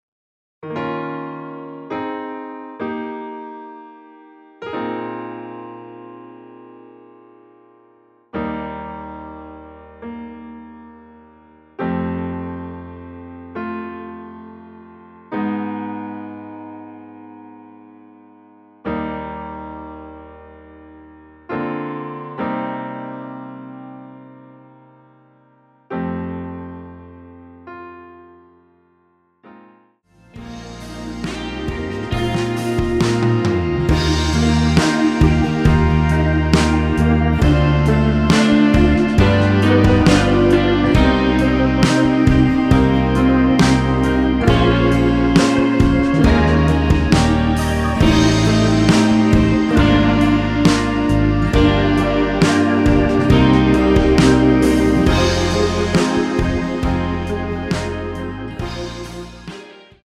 Eb
앞부분30초, 뒷부분30초씩 편집해서 올려 드리고 있습니다.